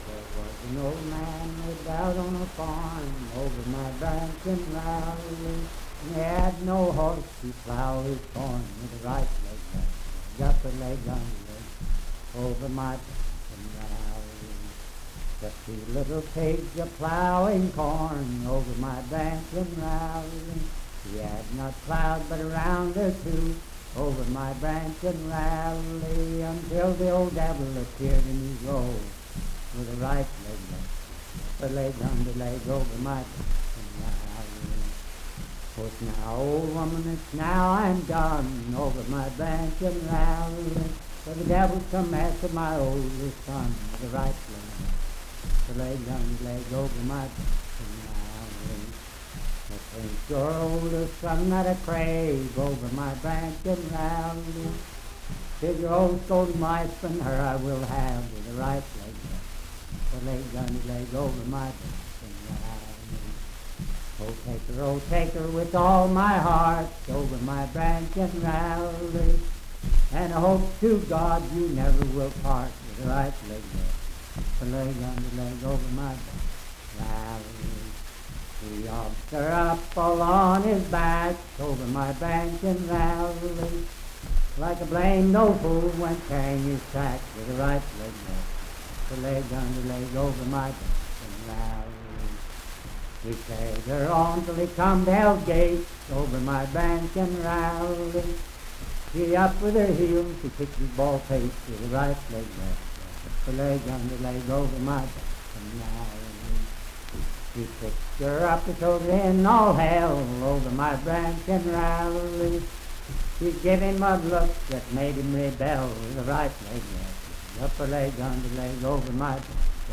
Unaccompanied vocal music and folktales
Verse-refrain 15(5w/R).
Voice (sung)
Wood County (W. Va.), Parkersburg (W. Va.)